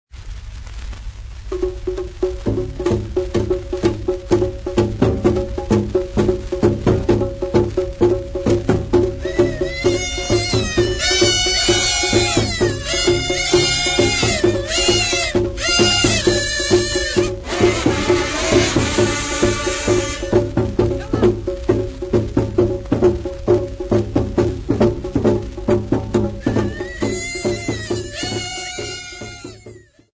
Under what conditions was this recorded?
Field recordings Africa Malawi City not specified f-mw